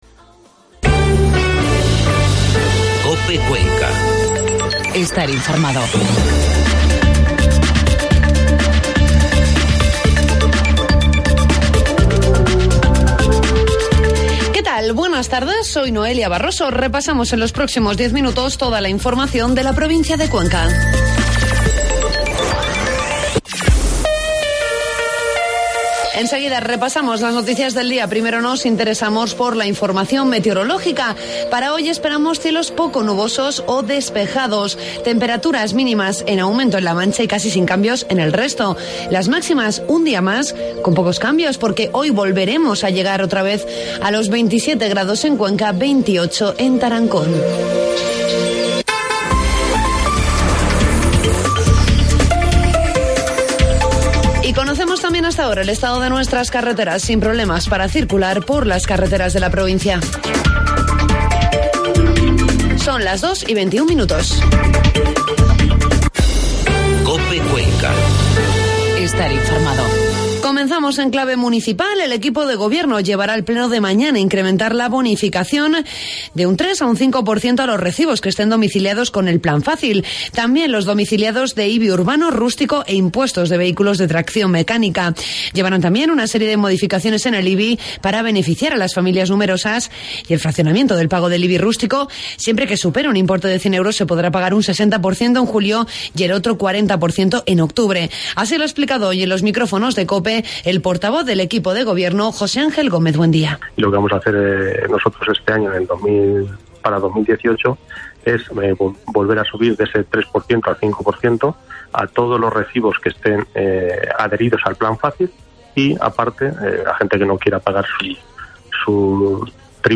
AUDIO: Informativo mediodía